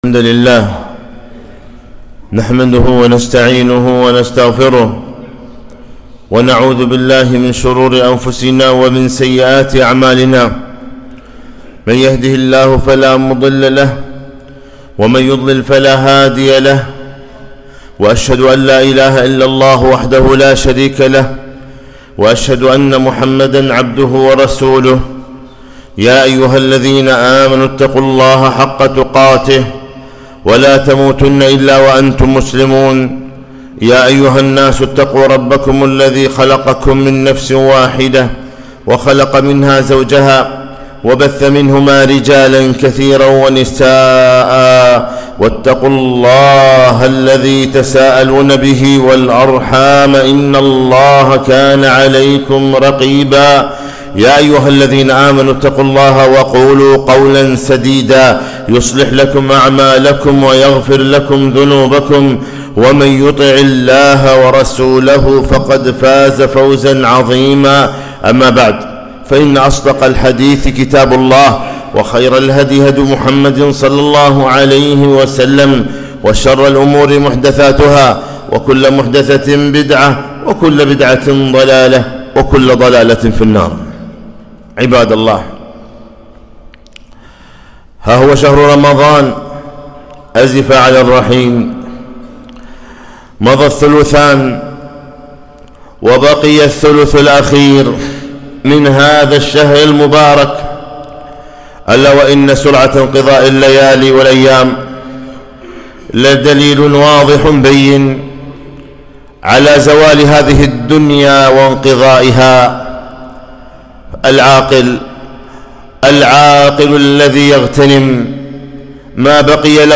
خطبة - ليالي العشر غنيمة وأجر